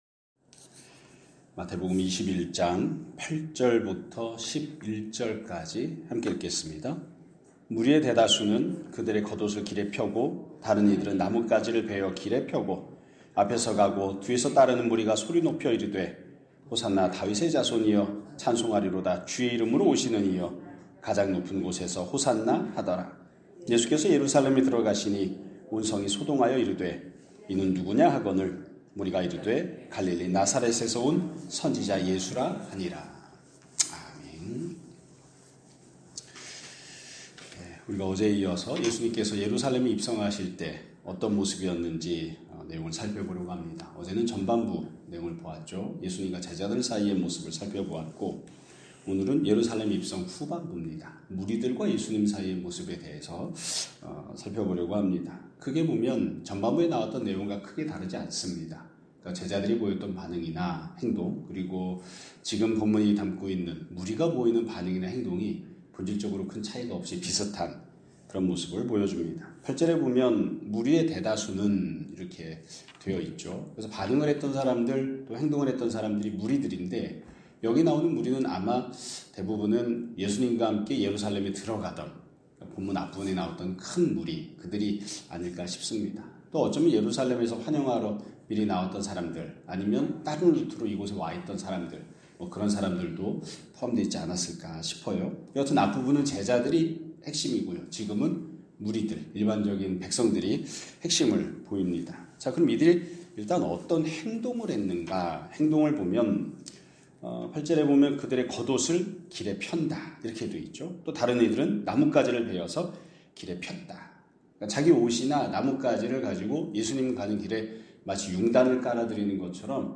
2026년 1월 22일 (목요일) <아침예배> 설교입니다.